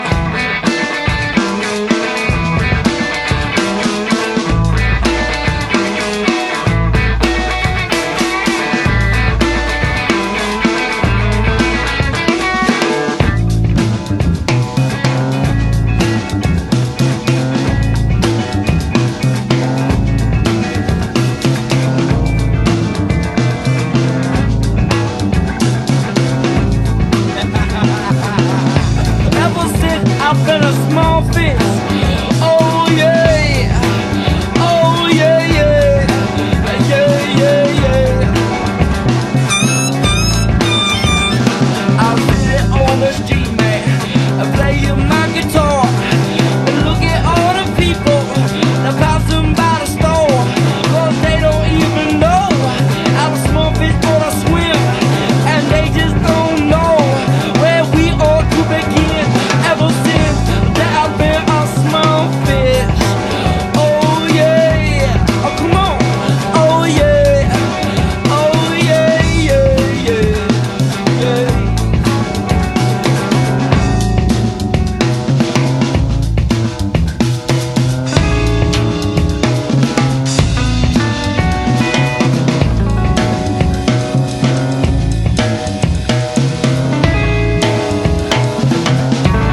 ROCK / 90''S～ / 00'S / BREAKBEATS